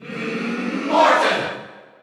Category: Crowd cheers (SSBU) You cannot overwrite this file.
Morton_Cheer_Dutch_SSBU.ogg